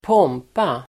Ladda ner uttalet
pompa substantiv, pomp Uttal: [²p'åm:pa] Böjningar: pompan Synonymer: prakt Definition: ståt, prakt Exempel: bröllopet firades med stor pompa (the wedding was celebrated with much pomp and ceremony (splendour))